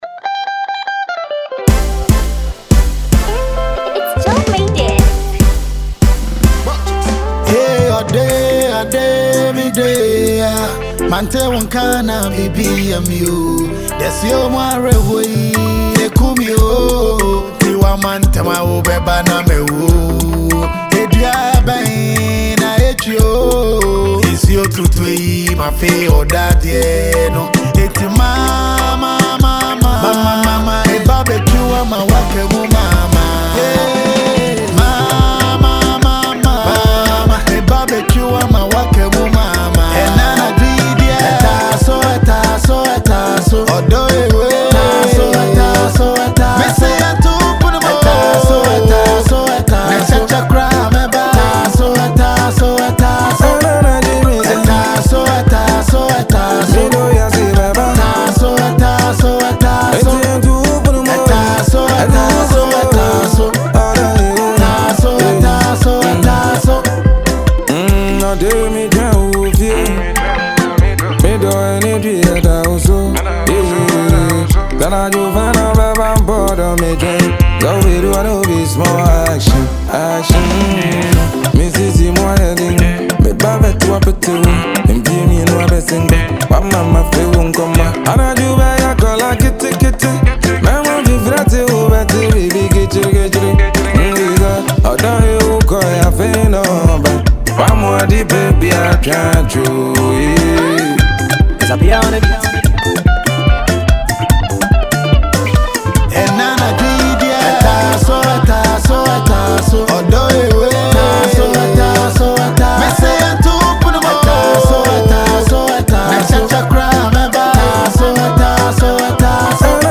highlife singer